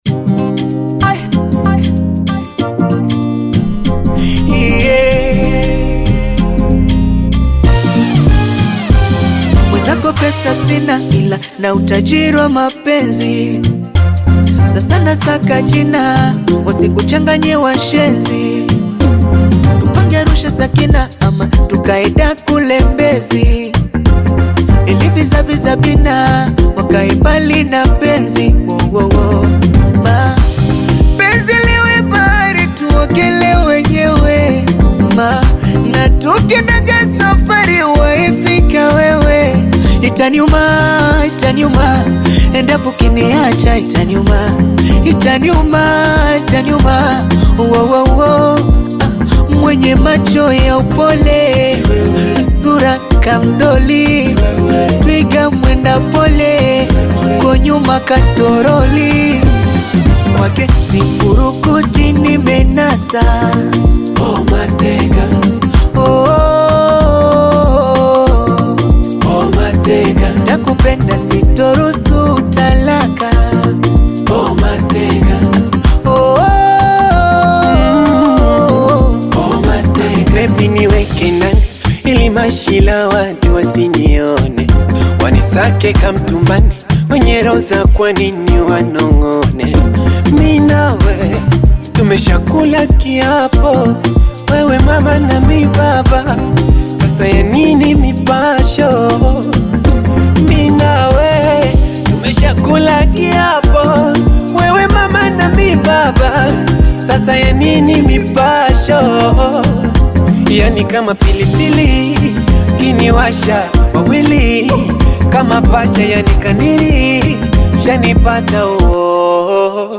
bongolanda
Tanzanian Bongo Flava artists
Bongo Flava